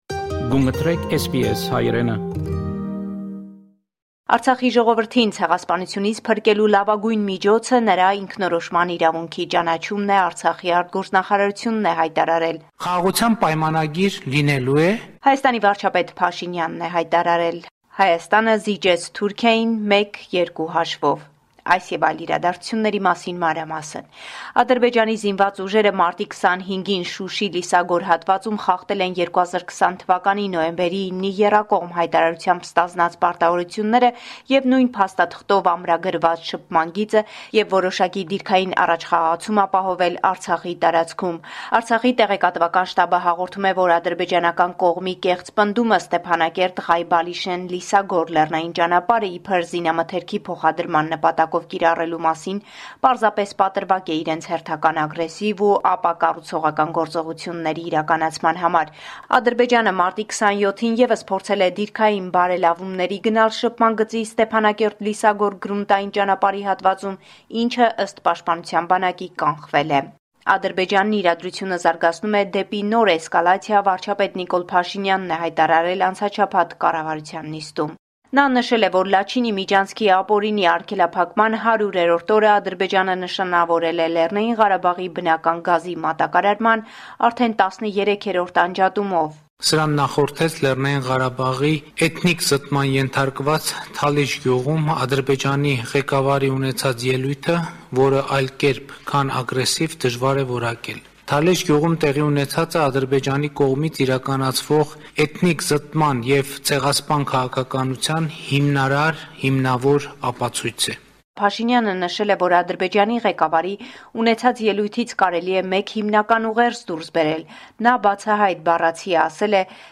Latest News from Armenia – 28 March 2023